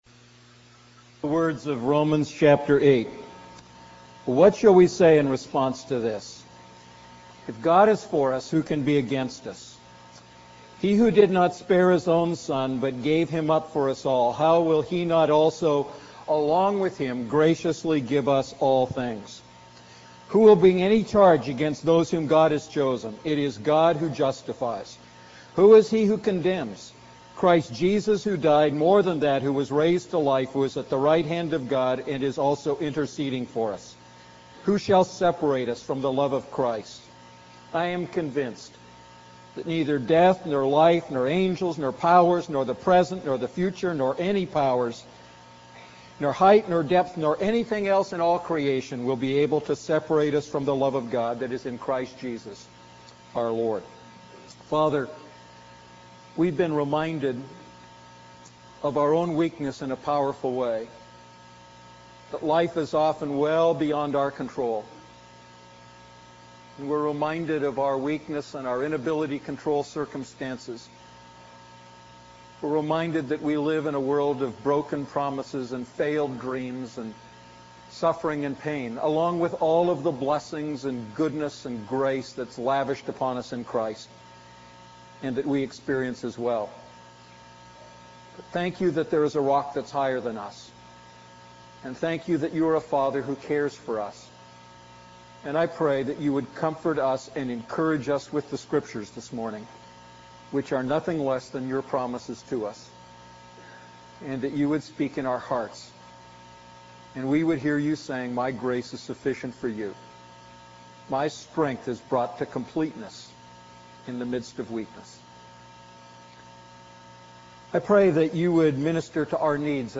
A message from the series "Prayer."